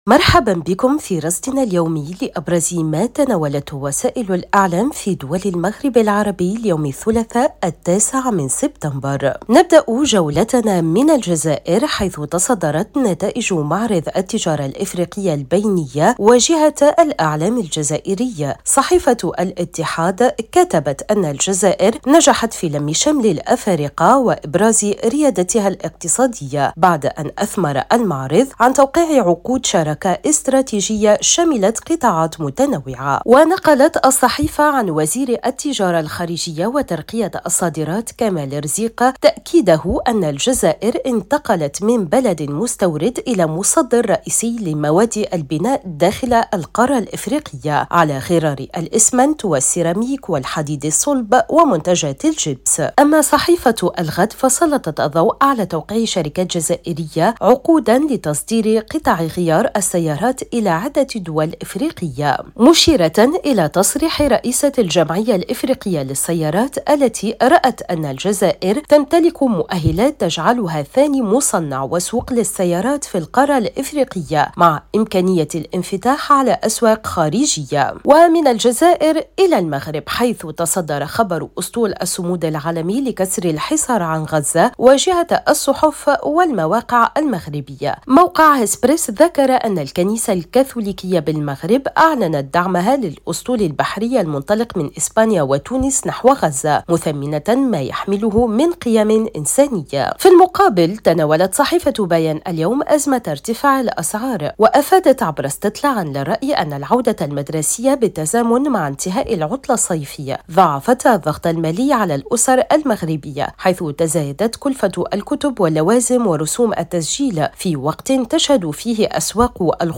صدى المغرب برنامج إذاعي يومي يُبث عبر إذاعة الشرق، يسلّط الضوء على أبرز ما تناولته وسائل الإعلام في دول المغرب العربي، بما في ذلك الصحف، القنوات التلفزية، والميديا الرقمية.